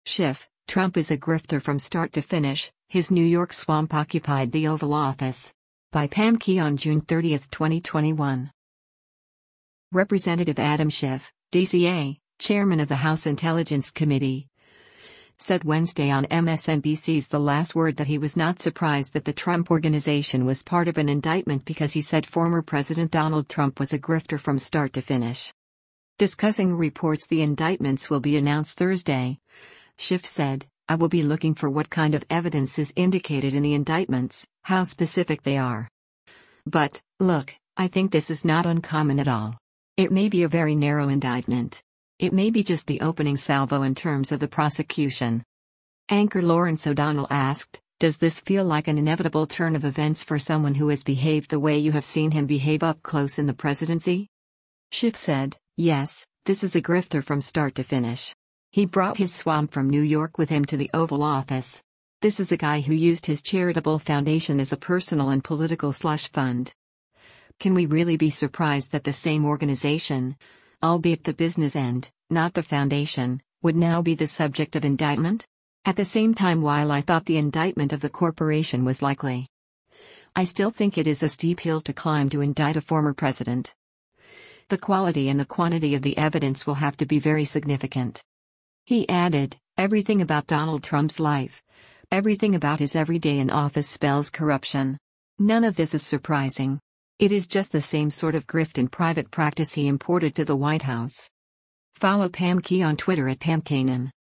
Representative Adam Schiff (D-CA), chairman of the House Intelligence Committee, said Wednesday on MSNBC’s “The Last Word” that he was not surprised that the Trump organization was part of an indictment because he said former President Donald Trump was a “grifter from start to finish.”
Anchor Lawrence O’Donnell asked, “Does this feel like an inevitable turn of events for someone who has behaved the way you have seen him behave up close in the presidency?”